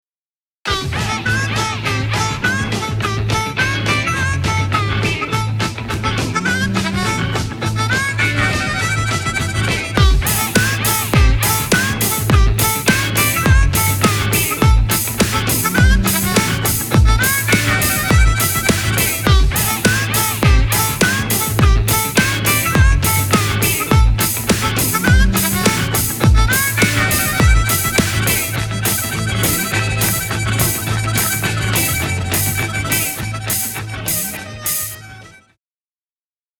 В первом файле, после первого прохода, снейр оригинала акцентируется, как СЛАБАЯ доля, то есть как я его и воспринимаю в соло проигрышах (гармоники, трубы и гитары).